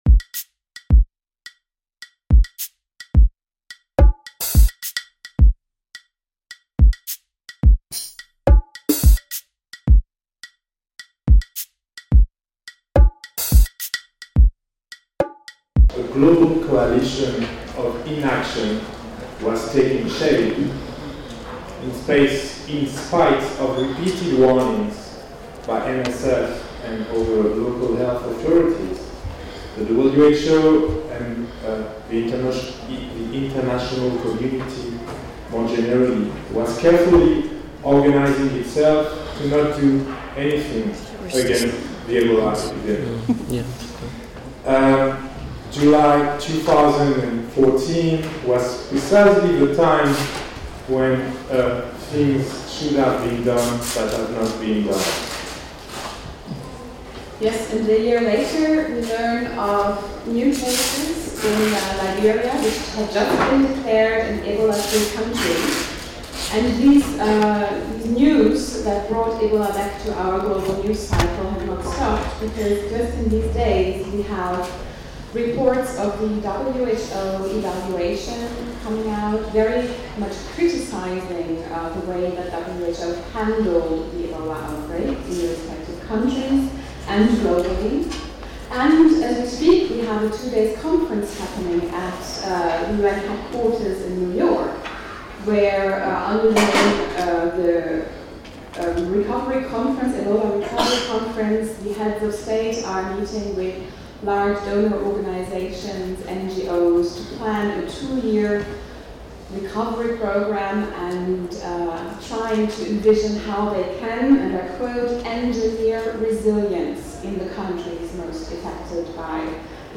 Table-ronde/round table